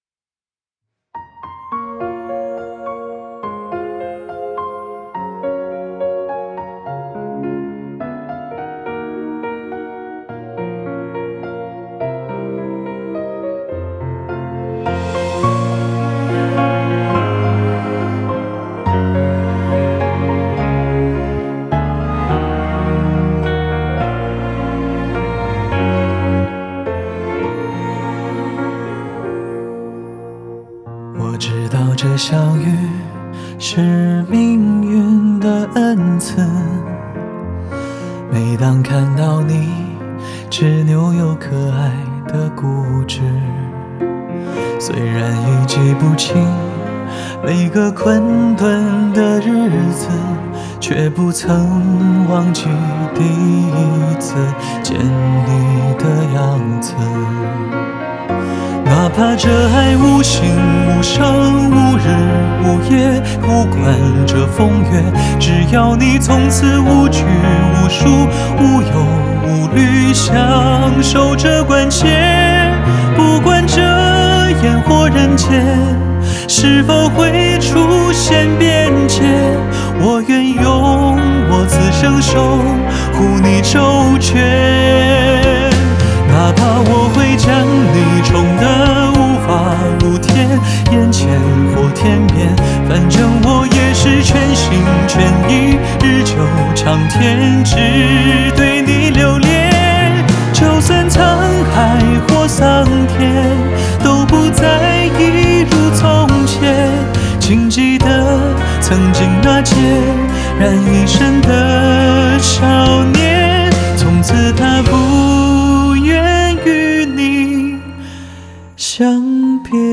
Ps：在线试听为压缩音质节选，体验无损音质请下载完整版
弦乐